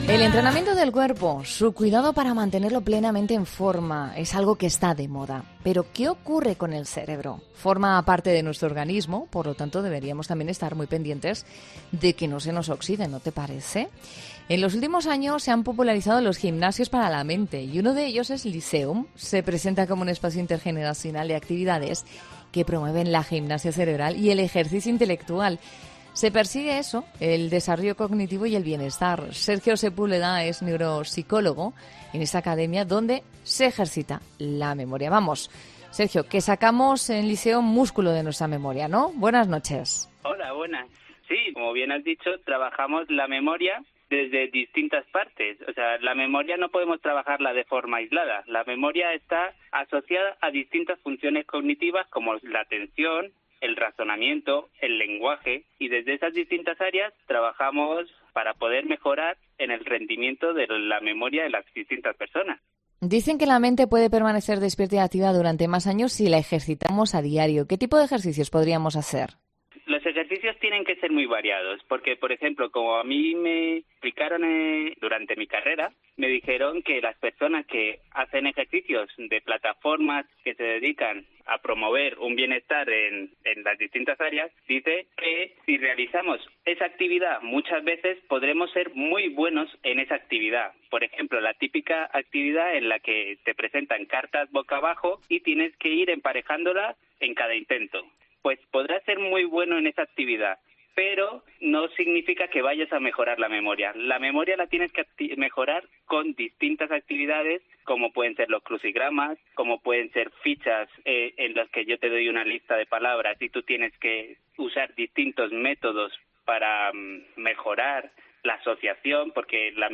Un neuropsicólogo desvela como mantener tu mente activa y prevenir enfermedades como el Alzhéimer